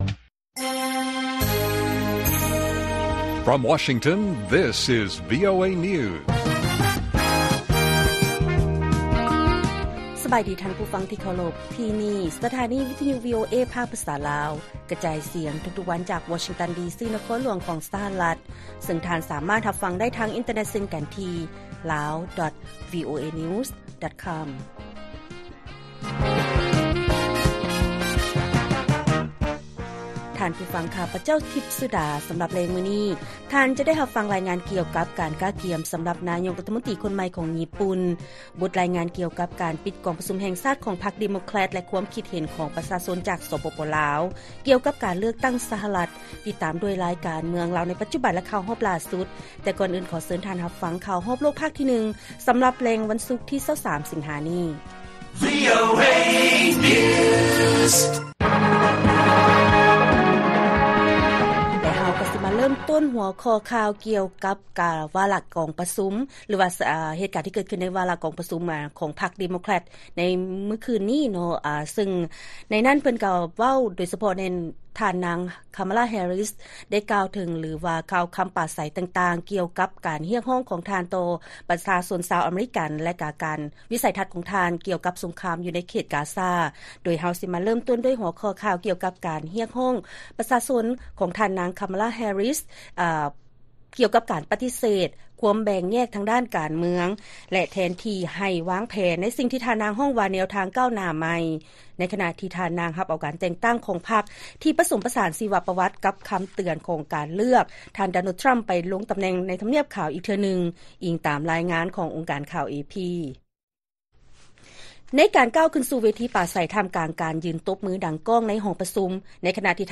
ລາຍການກະຈາຍສຽງຂອງວີໂອເອ ລາວ: ທ່ານນາງ ຄາມາລາ ແຮຣິສ ເຊີນຊວນຜູ້ມີສິດລົງຄະແນນສຽງ ກໍານົດ 'ແນວທາງກ້າວໜ້າໃຫມ່'